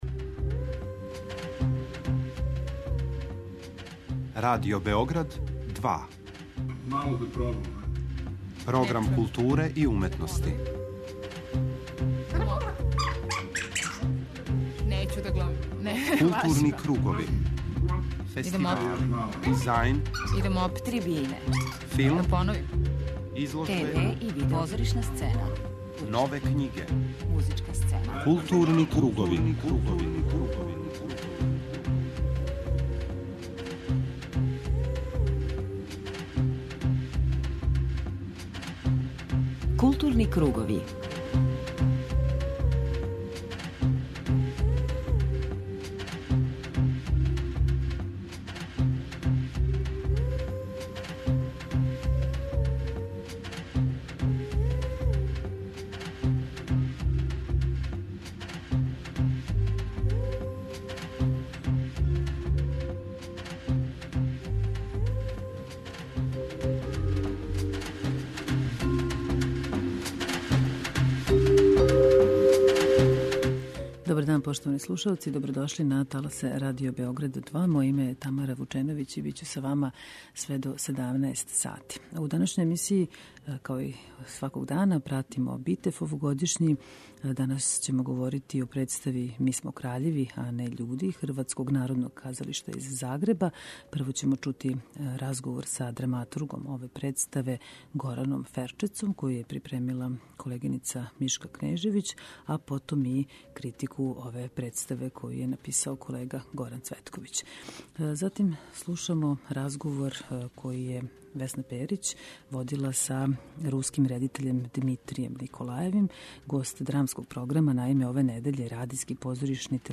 преузми : 52.68 MB Културни кругови Autor: Група аутора Централна културно-уметничка емисија Радио Београда 2.